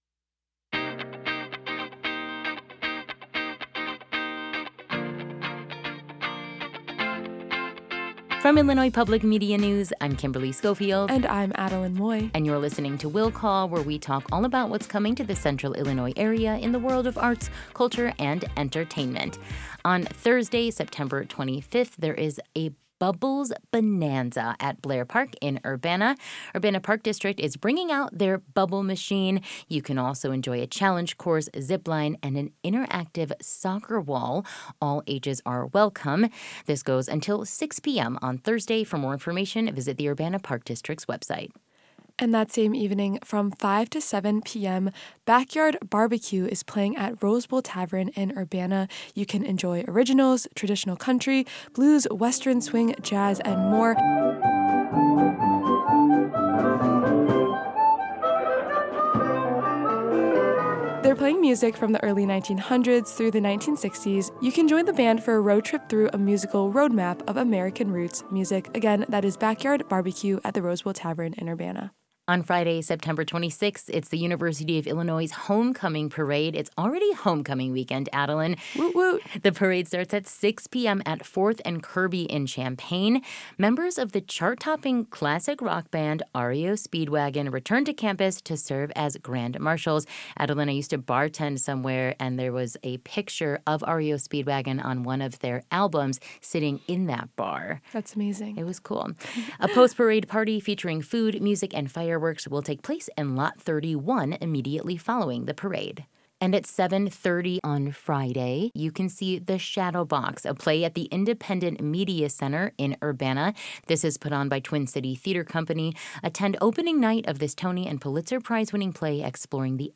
talk about weekend events on IPM News AM 580 and FM 90.9